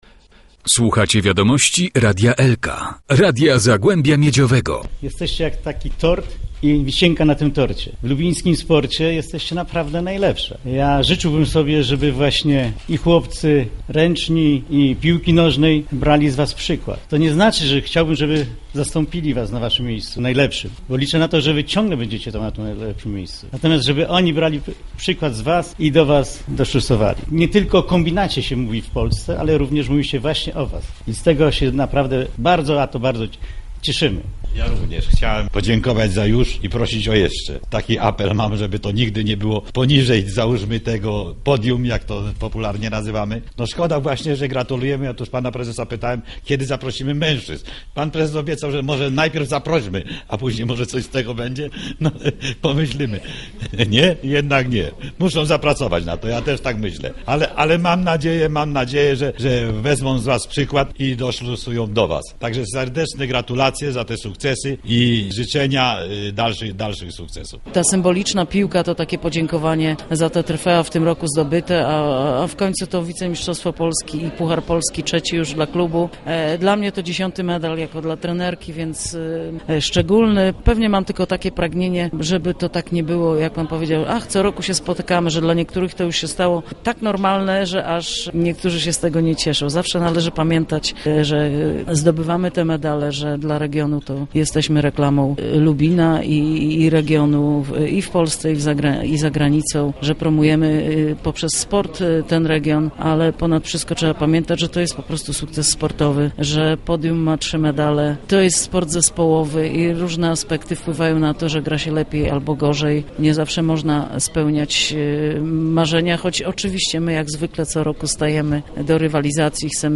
Adam Myrda, starosta powiatu lubińskiego; Andrzej Górzyński, przewodniczący Rady Miejskiej w Lubinie